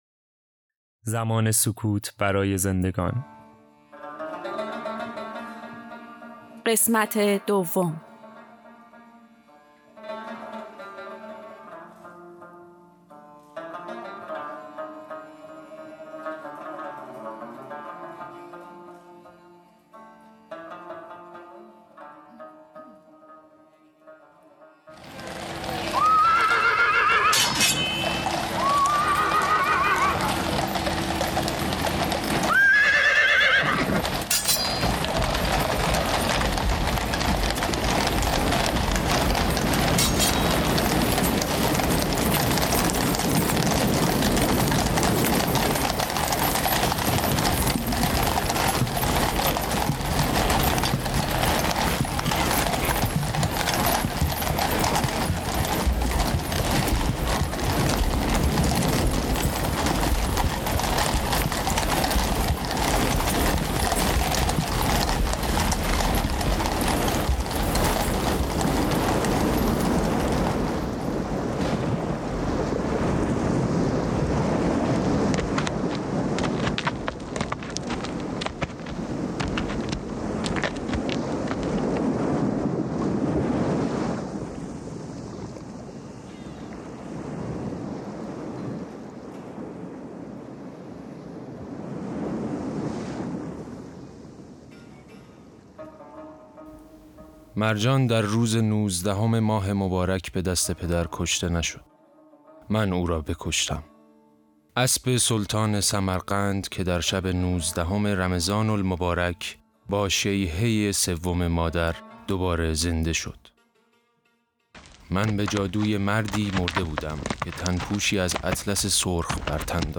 پادکست | نمایش رادیویی زمان سکوت برای زندگان
"زمان سکوت برای زندگان" روایت‌ روزهای ضربت‌ خوردن حضرت علی(ع) است که از زبان چندشخصیت فرعی روایت می‌شود. این نمایشنامه توسط چند هنرمند تئاتر مشهد به‌صورت اختصاصی برای مخاطبان شهرآرانیوز اجرا شده است.